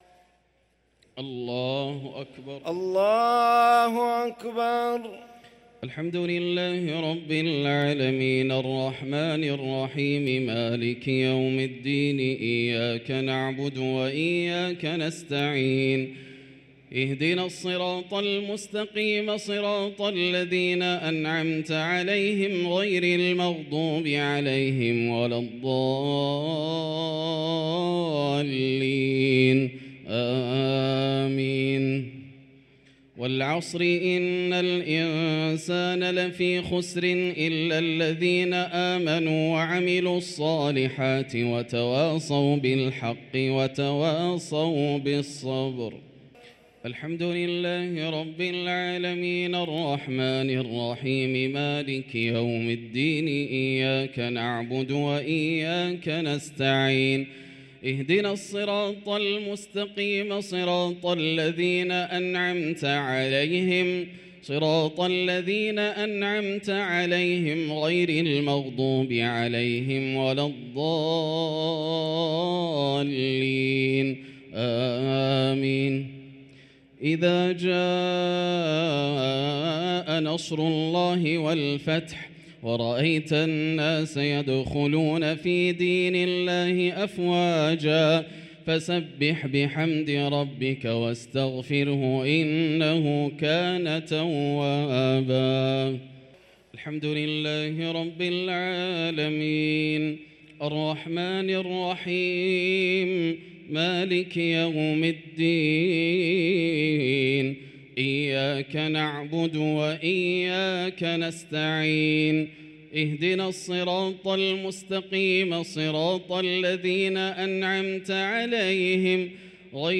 الشفع والوتر ليلة 18 رمضان 1444هـ | Witr 18 st night Ramadan 1444H > تراويح الحرم المكي عام 1444 🕋 > التراويح - تلاوات الحرمين